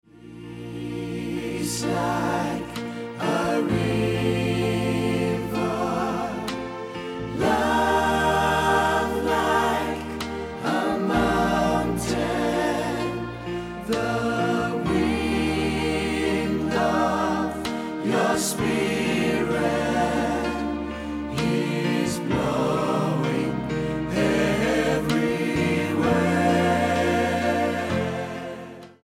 MOR / Soft Pop